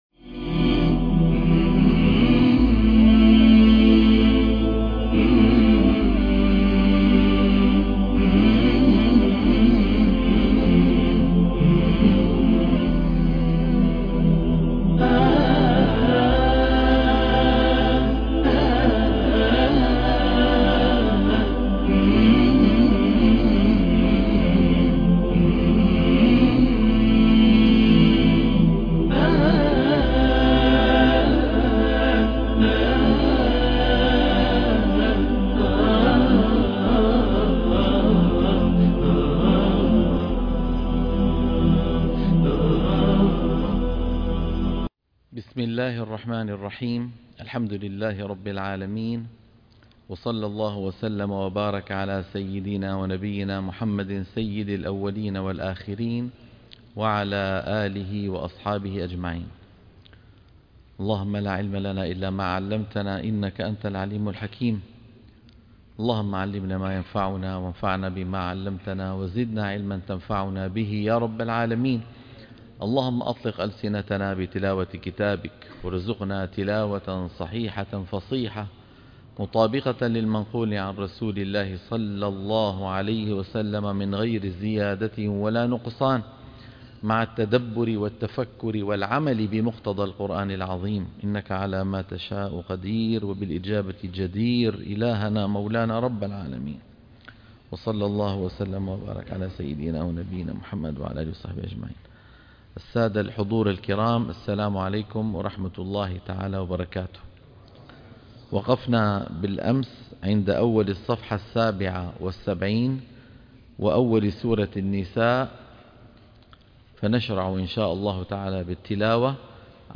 تصحيح التلاوة تلقين الصفحة 77